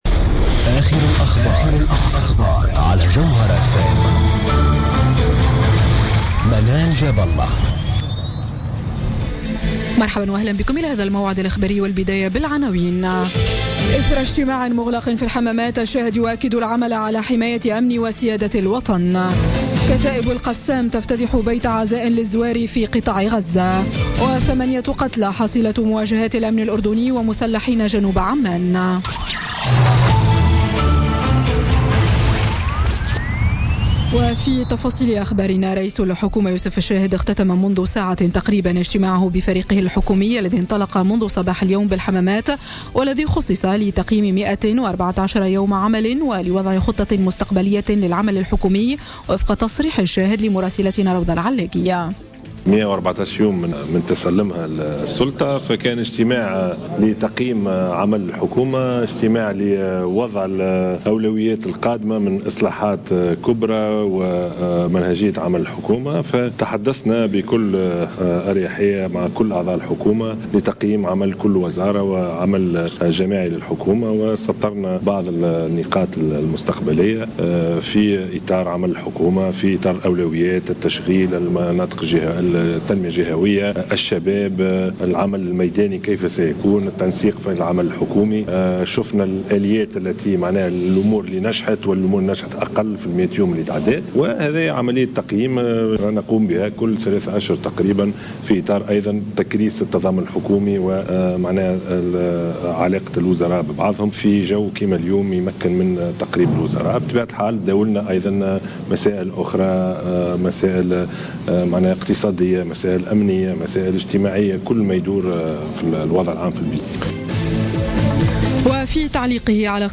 نشرة أخبار السابعة مساء ليوم الأحد 18 ديسمبر 2016